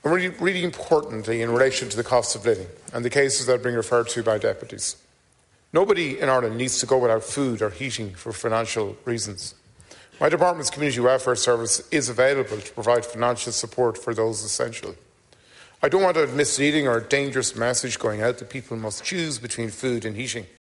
In response, the Minister for Social Protection says he recognises people with disabilities face unavoidable additional costs.